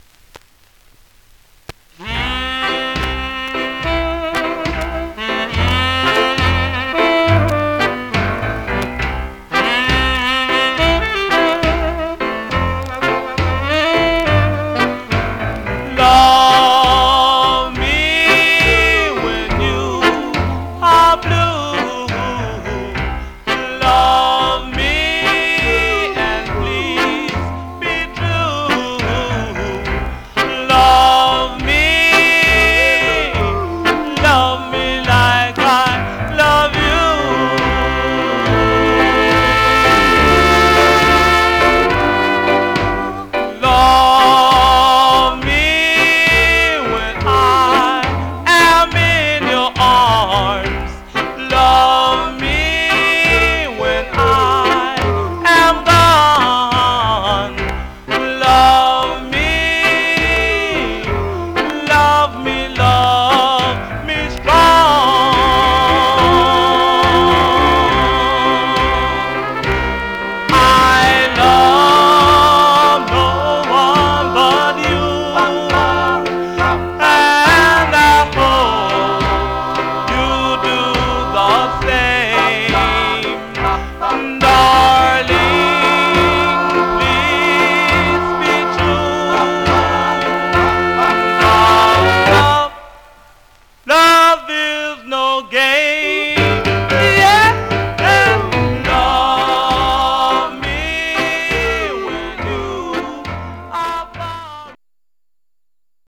Condition Surface noise/wear Stereo/mono Mono
Male Black Groups